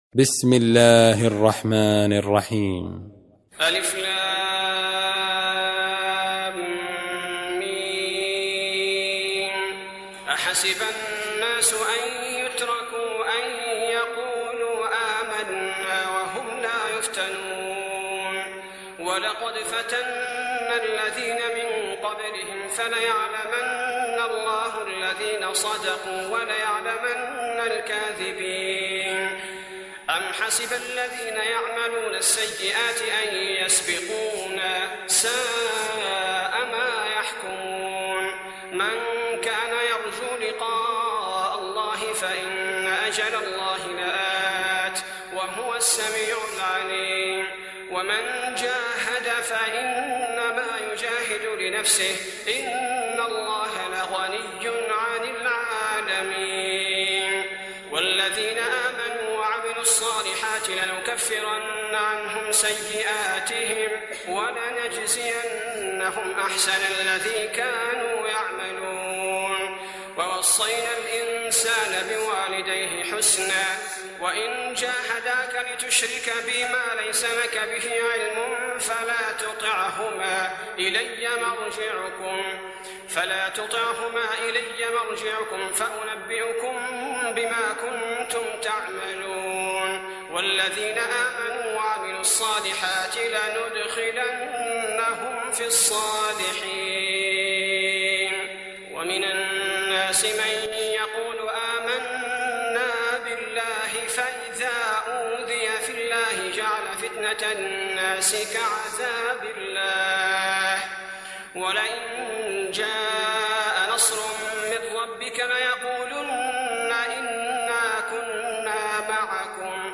سورة العنكبوت Surat Al-Ankbut > مصحف الشيخ عبدالباري الثبيتي > المصحف - تلاوات الحرمين